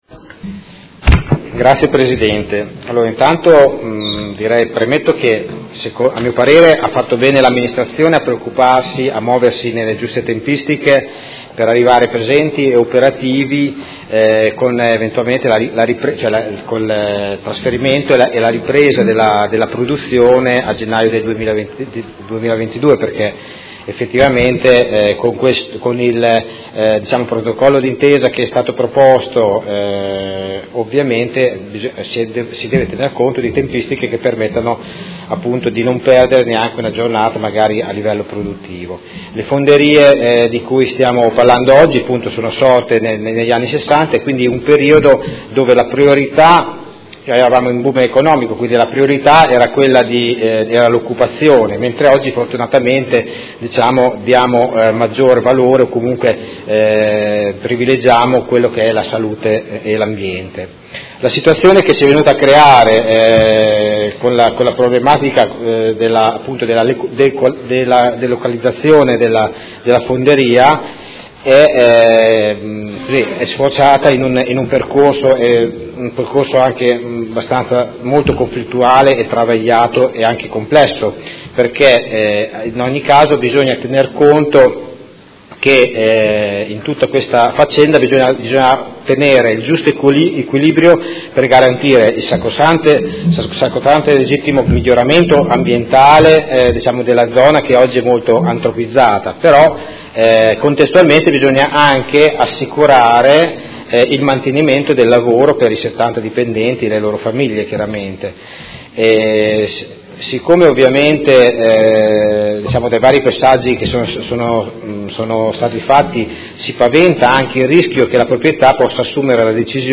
Seduta del 21/12/2017.